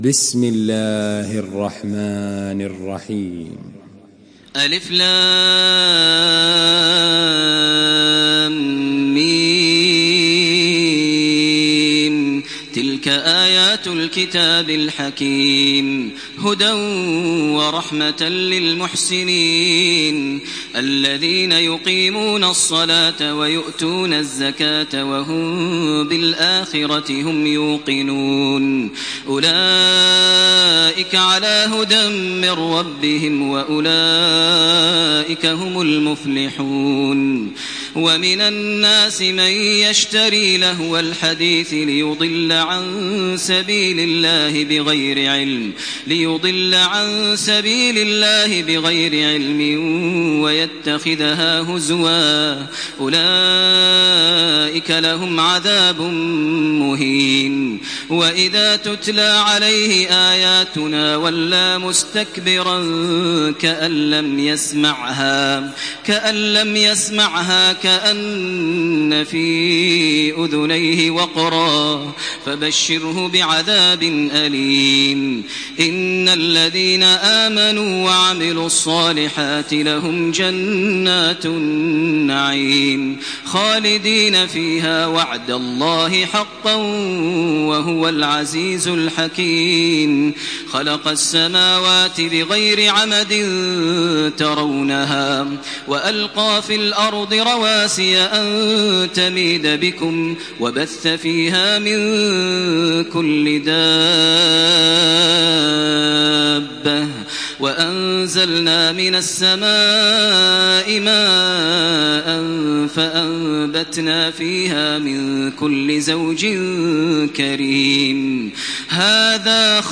Surah لقمان MP3 by تراويح الحرم المكي 1428 in حفص عن عاصم narration.
مرتل